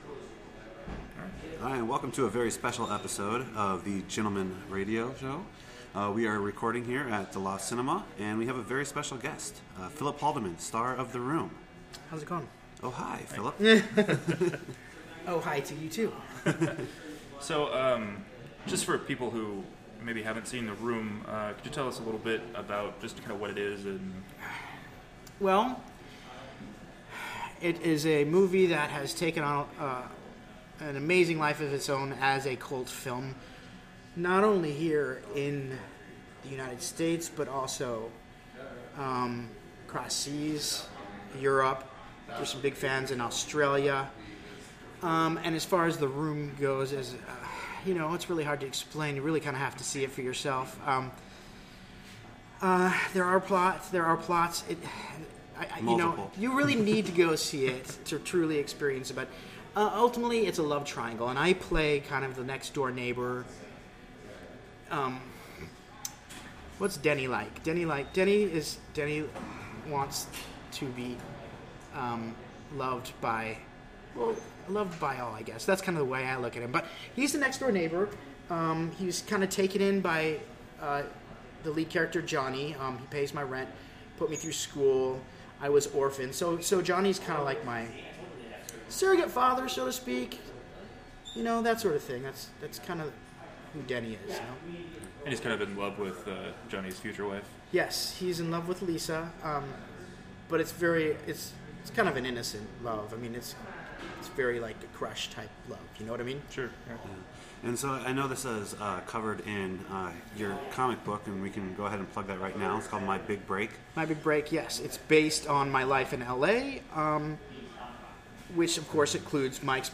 It is a very fun conversation and you need to check it out whether you have seen the Room or not and if you haven’t seen t he Room , you need to that immediately.